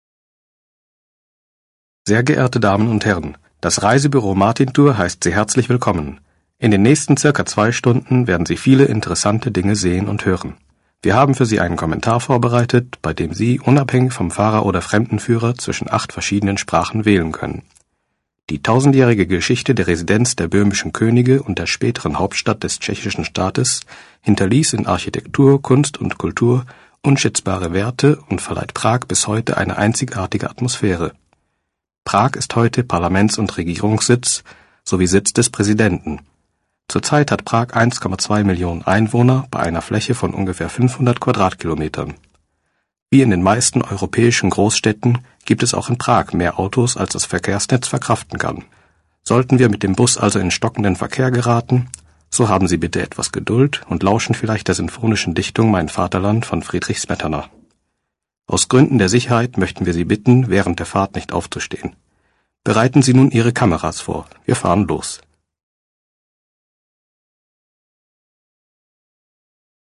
commentary-de.mp3